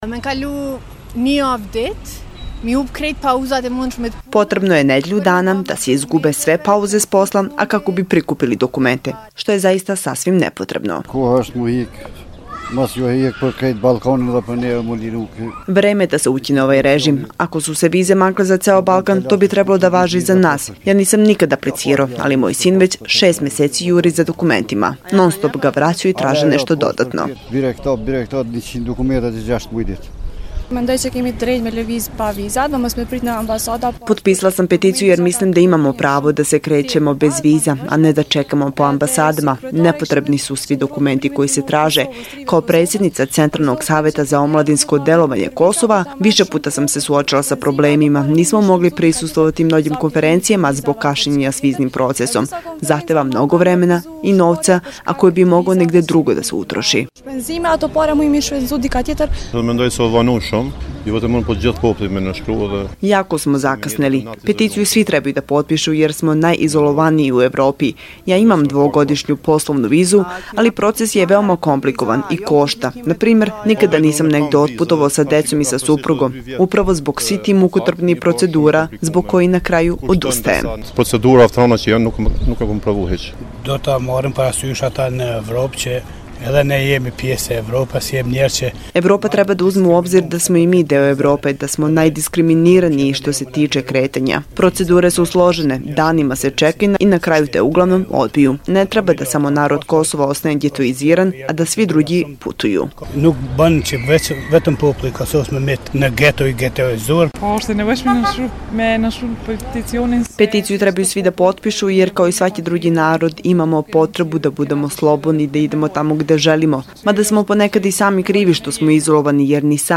To je diskriminacija i mukotrpan proces, kažu građani Prištine koji su danas potpisali peticiju.